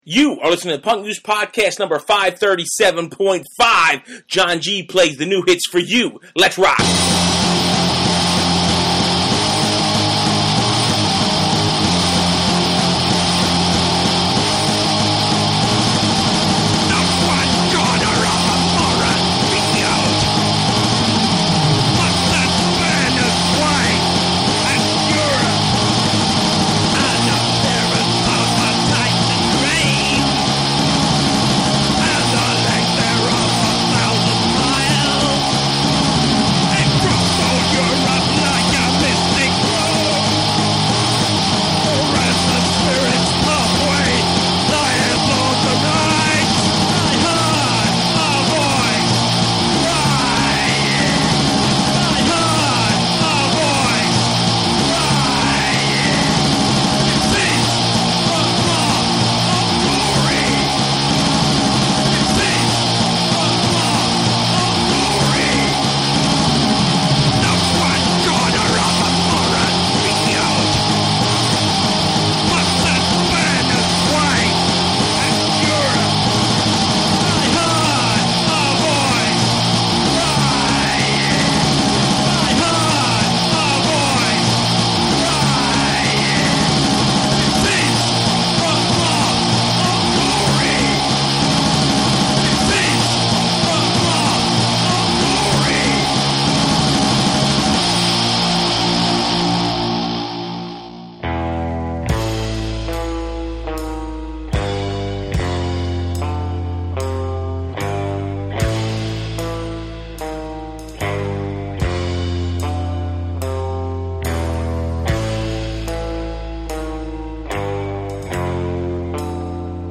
plays new tracks and reissues